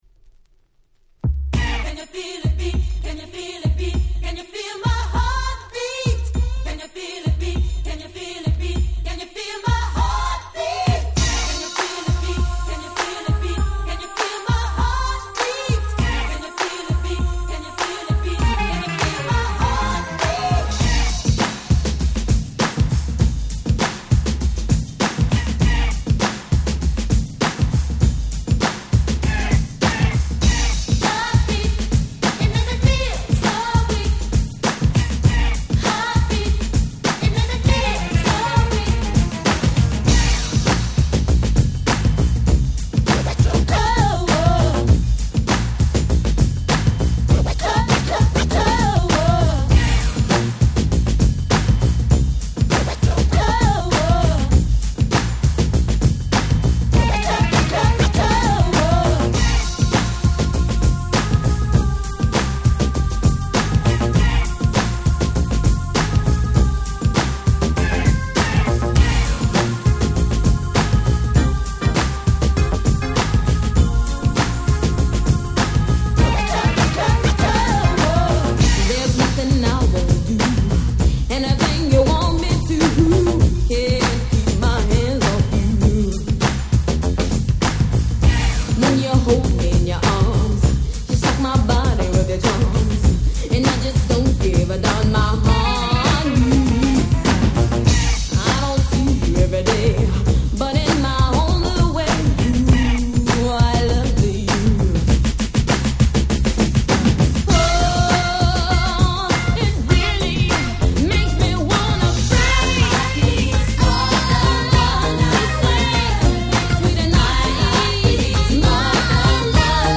90' Smash Hit R&B !!